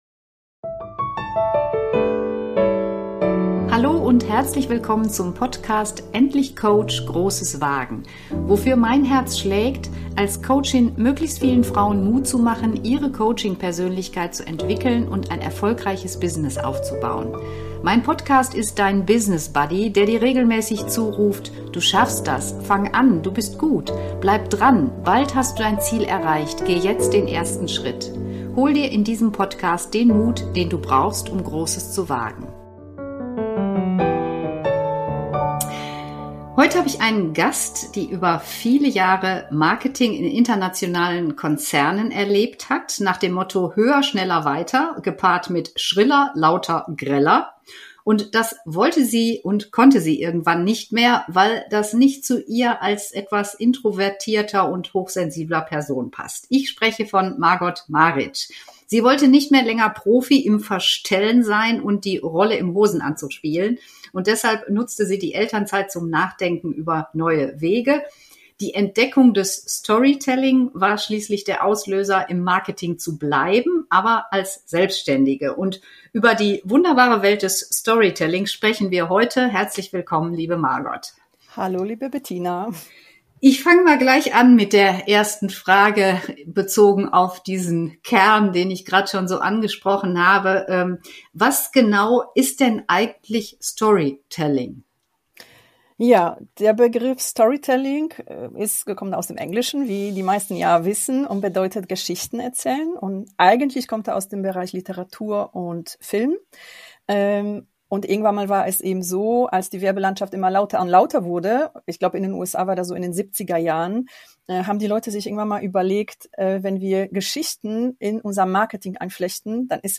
#051 Interview